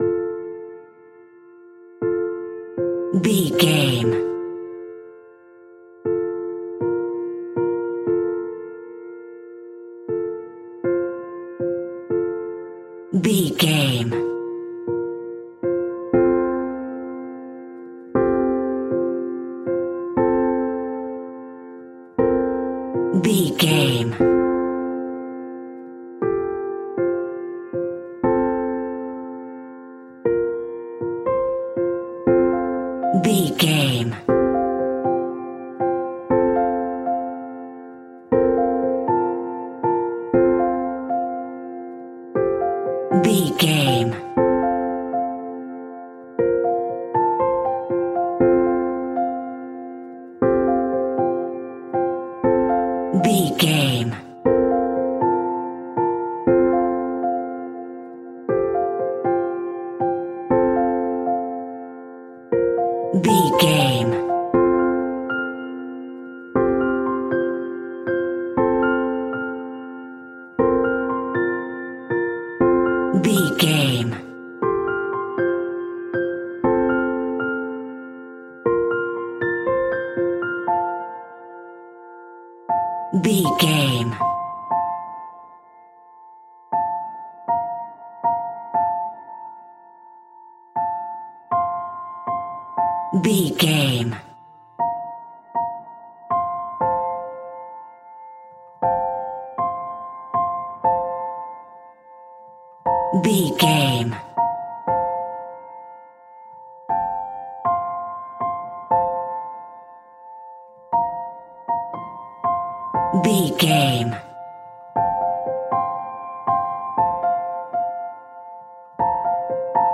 Ionian/Major
Slow
tranquil
synthesiser
drum machine